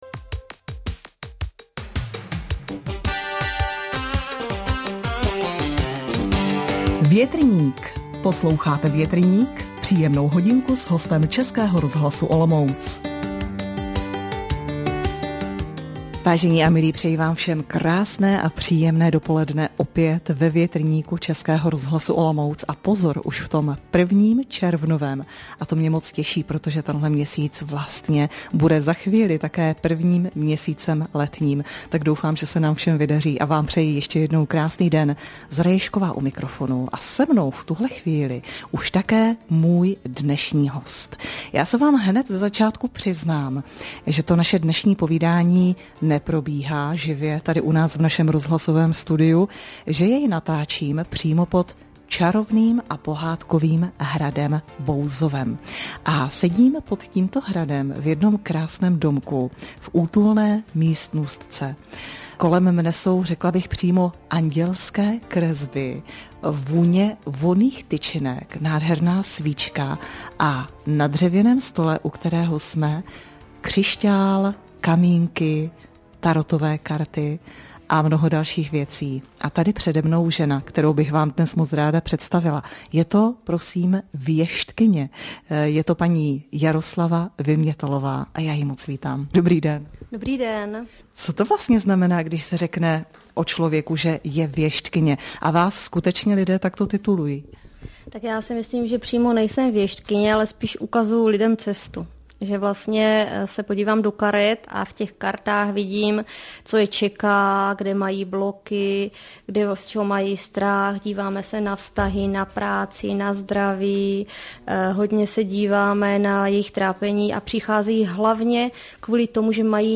Rozhovor na Českém rozhlase Olomouc v pořadu Větrník